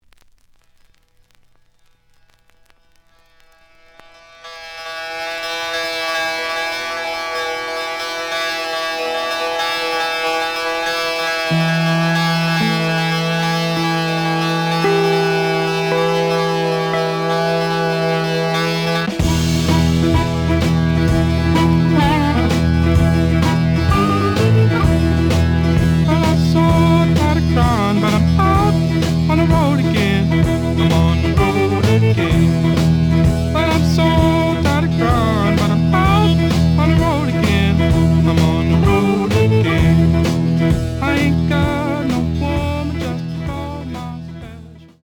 The audio sample is recorded from the actual item.
●Genre: Rock / Pop
Looks good, but slight noise on both sides.)